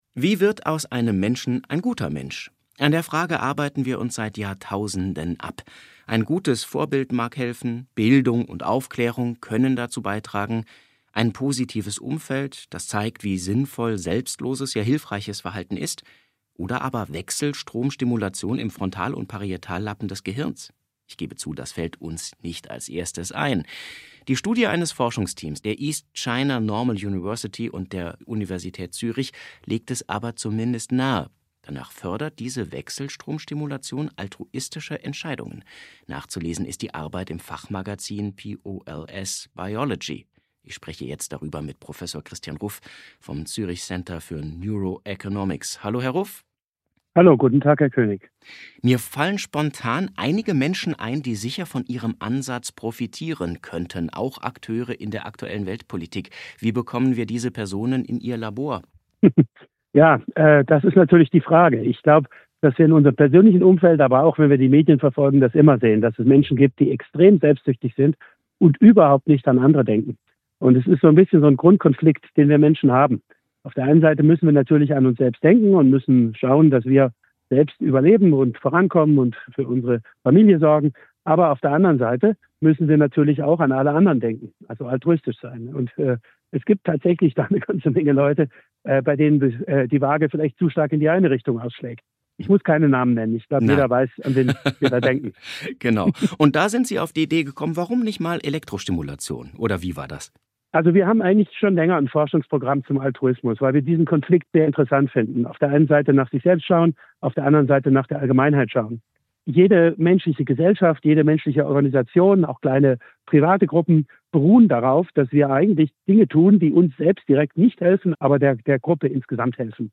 1902-impuls-interview-hirnstimulation-und-altruismus-podcast.mp3